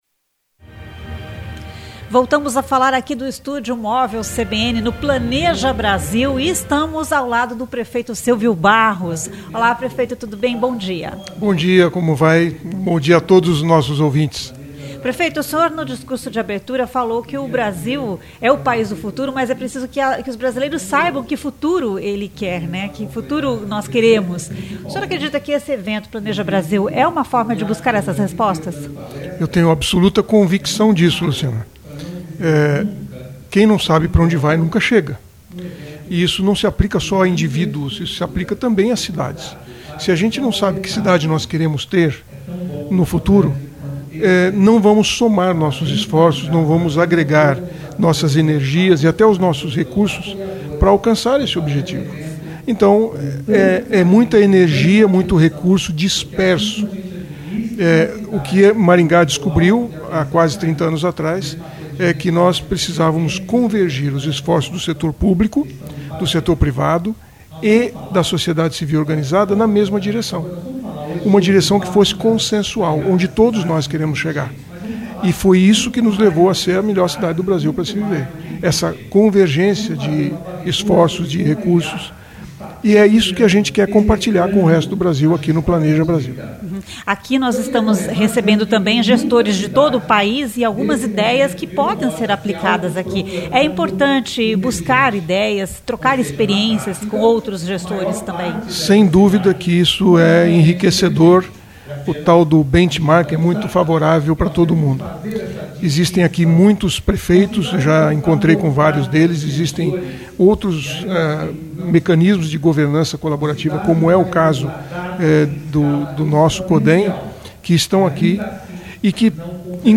A entrevista foi realizada no estúdio móvel CBN instalado no local do evento.